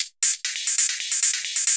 Index of /musicradar/ultimate-hihat-samples/135bpm
UHH_ElectroHatA_135-01.wav